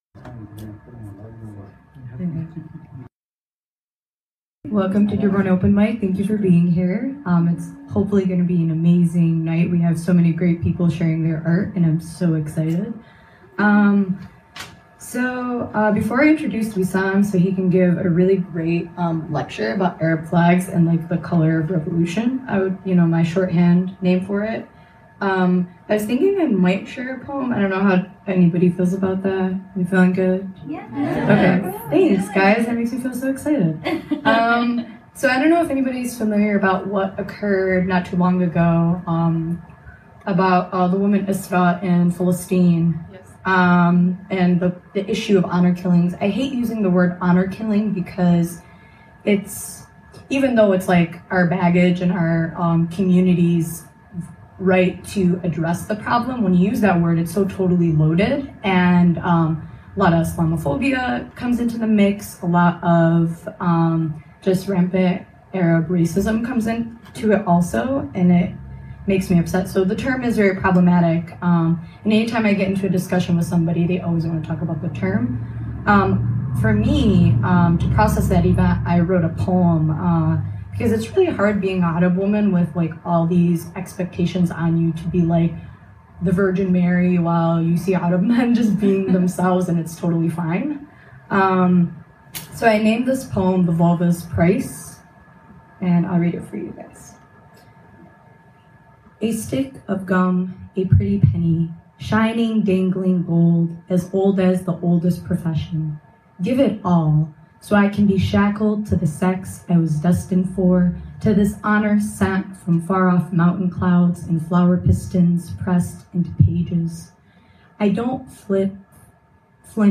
9-18-19 PODCAST: Dearborn Open Mic English